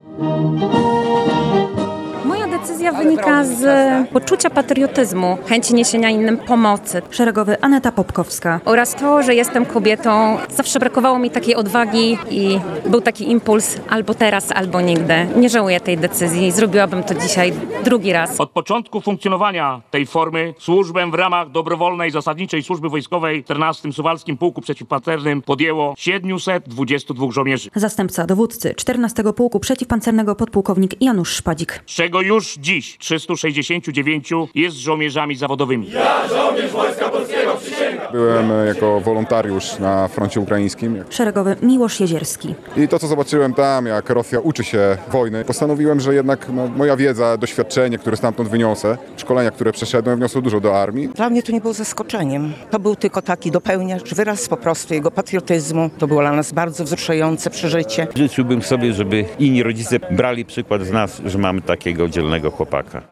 relacja
W Suwałkach na placu apelowym 14. Pułku Przeciwpancernego przysięgę złożyło blisko 50 żołnierzy dobrowolnej zasadniczej służby wojskowej.